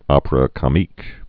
(ŏpər-ə kŏ-mēk, ŏprə, ô-pā-rä kô-mēk)